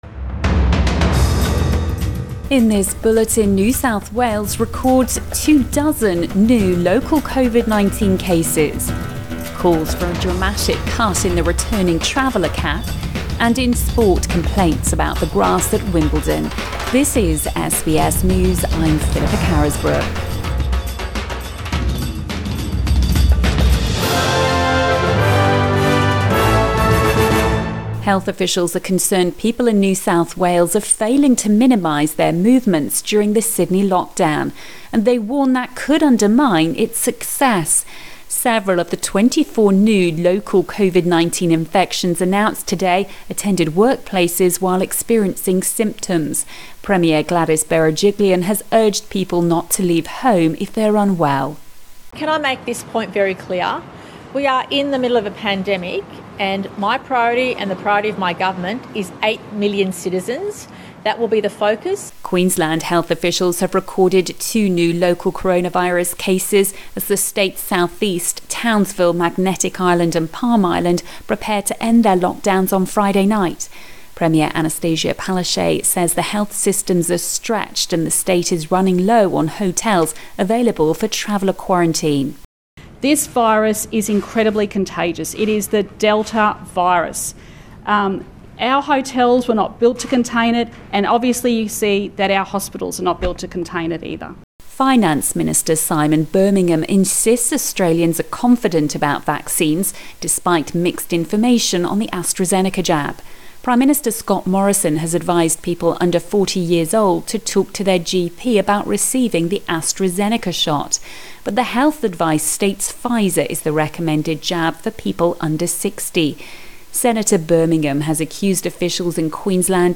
PM bulletin 1 July 2021